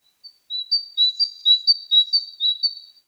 ANIMAL_Bird_Singing_Spring_mono.wav